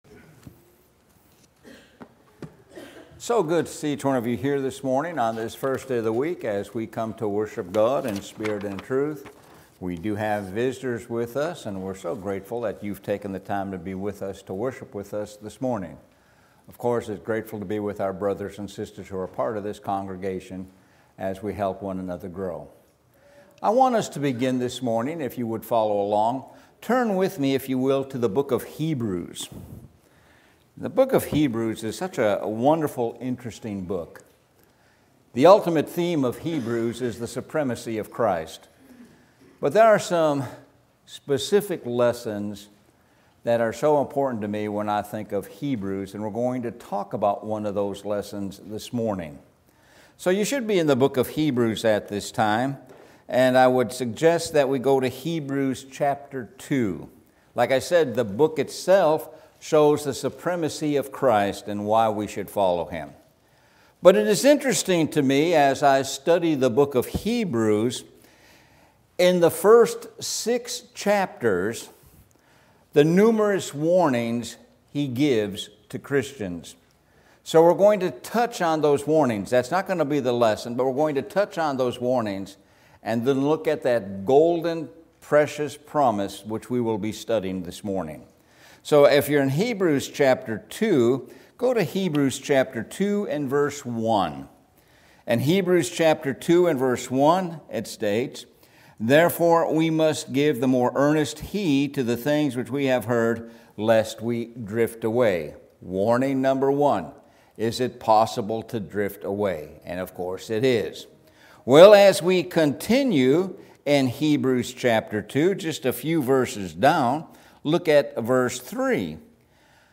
Sun AM Sermon – Anchorof the Soul